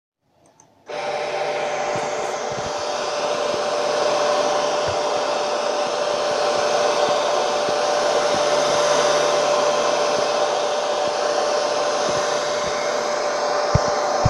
Chorro de aire o aspirador contínuo
Grabación sonora en la que se escucha el sonido contínuo de un aspirador o un secador en funcionamiento, bien soltando un chorro de aire o aspirándolo, según su función.
Sonidos: Hogar